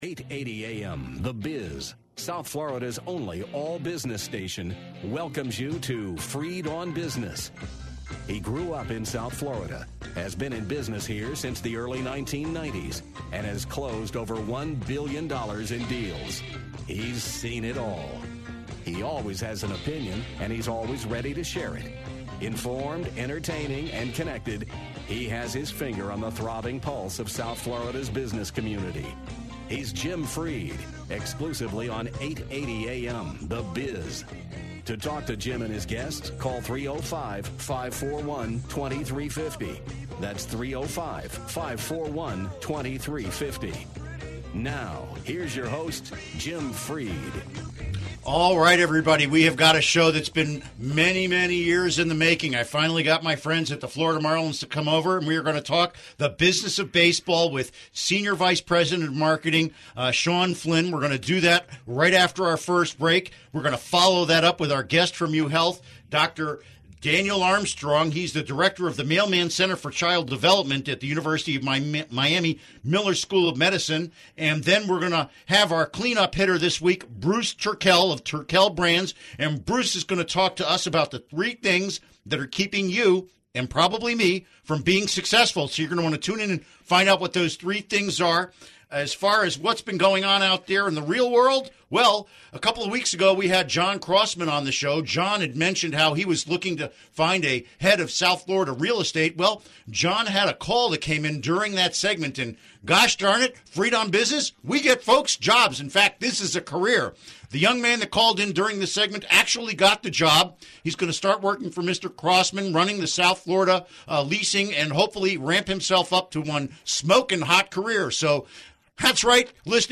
It will be irreverent and full of useful content.
We laugh and joke and actually get some good information out there even th